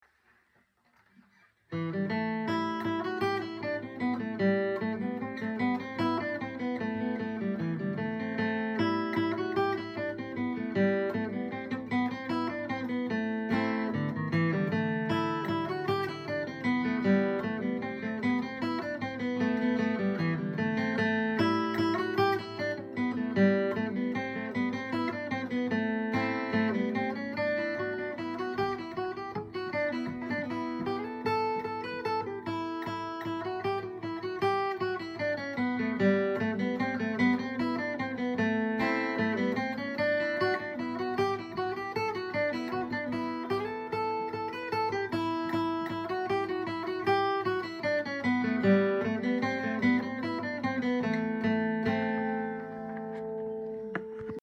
Key: Ador
Form: March or air*
Source: Trad.
Region: Originally Ireland, probably Northern Ireland